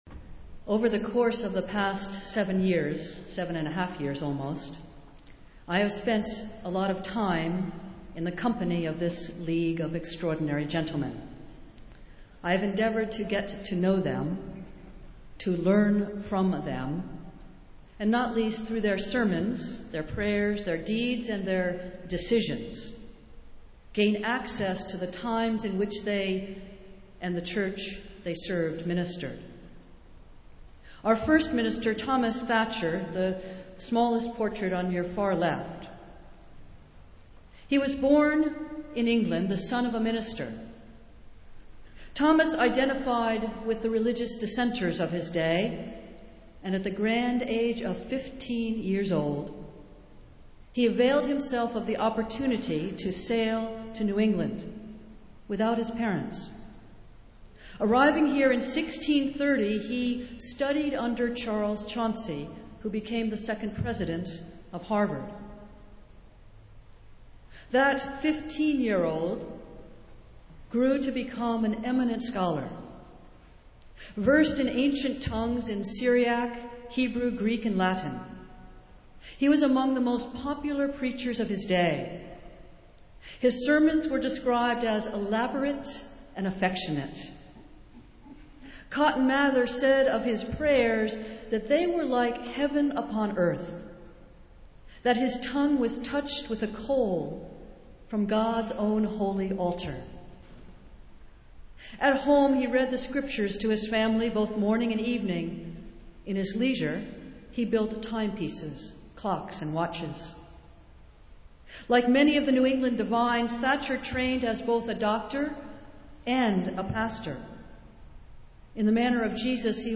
Festival Worship - Father's Day